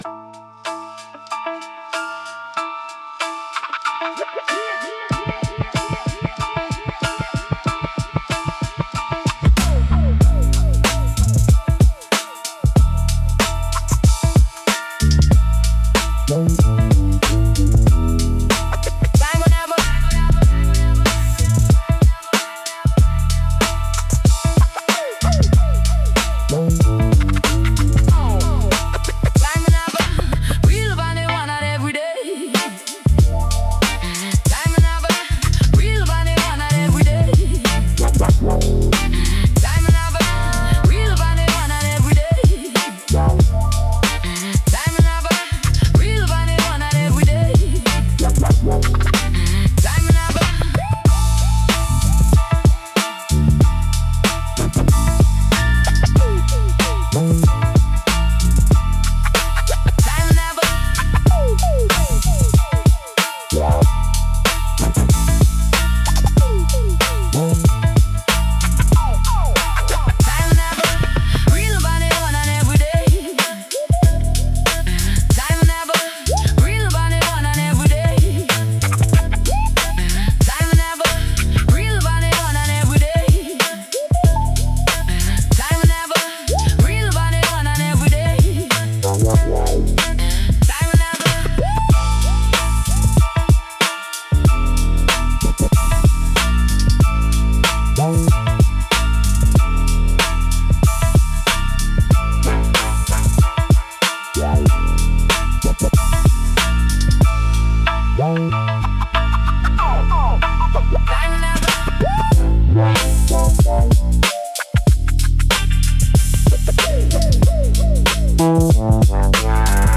Hip Hop, Boom Bap, Moody, Upbeat, Scratching